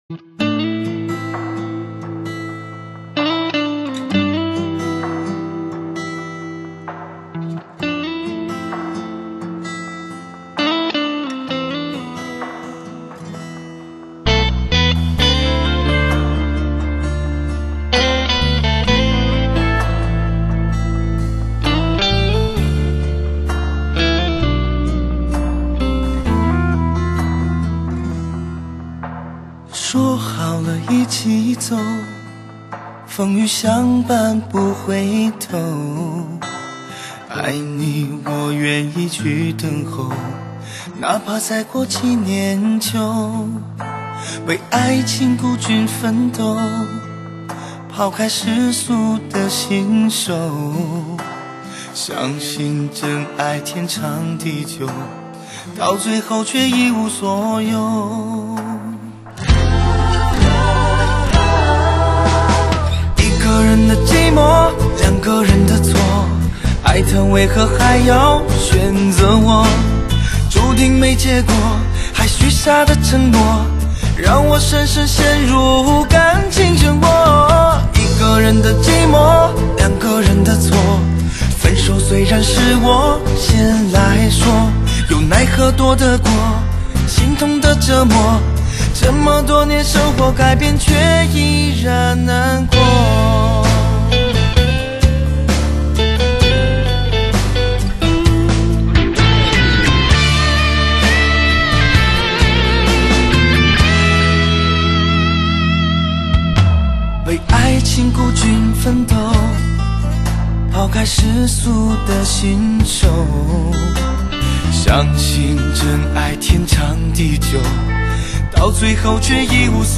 华语男歌手